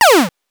laser_shot_1.wav